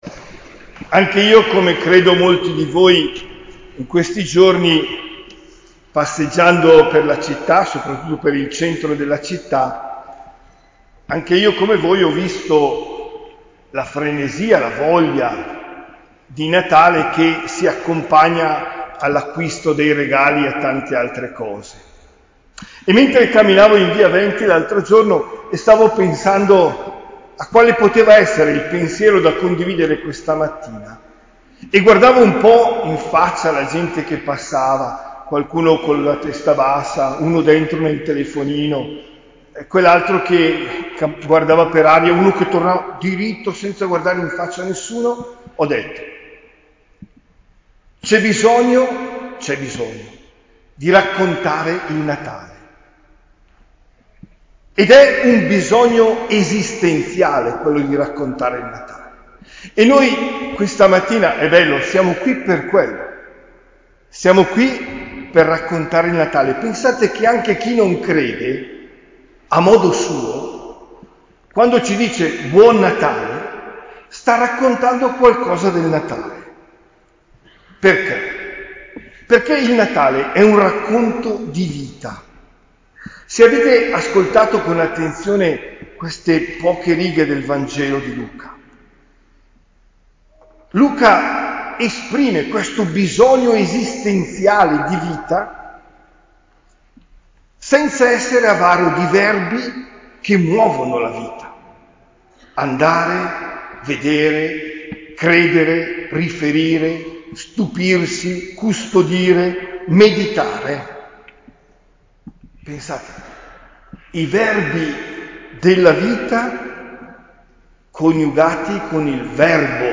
OMELIA DEL 25 DICEMBRE 2022